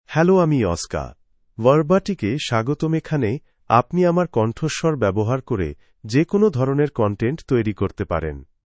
Oscar — Male Bengali AI voice
Oscar is a male AI voice for Bengali (India).
Voice sample
Listen to Oscar's male Bengali voice.
Male
Oscar delivers clear pronunciation with authentic India Bengali intonation, making your content sound professionally produced.